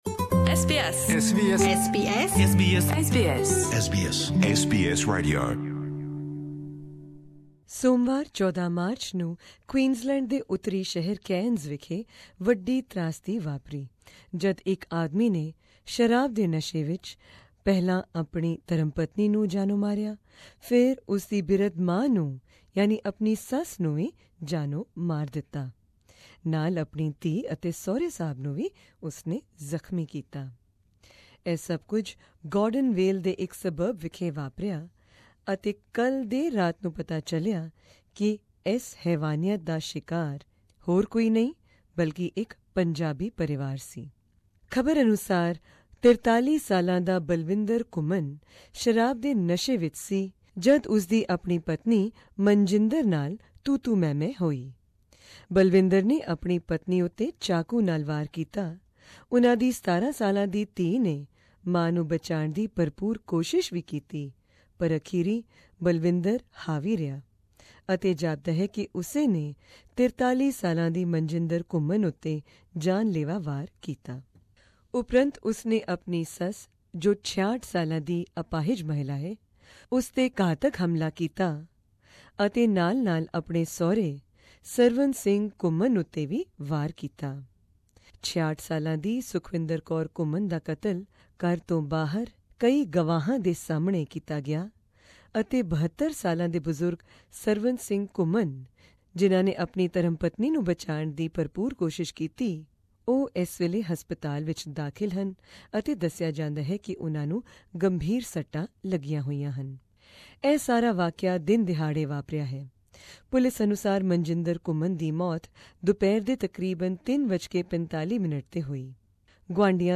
Queensland Police holds a press conference about the horrific double homicide in Gordonvale, Cairns
Click on the audio link above, to hear excerpts from the press conference held by Queensland Police, and also to hear more details of the crime that has shaken the entire community!